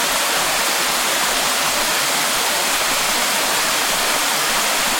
Waterfall
A medium waterfall cascading into a pool with steady rush, mist, and splashing base
waterfall.mp3